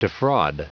Prononciation du mot defraud en anglais (fichier audio)
Prononciation du mot : defraud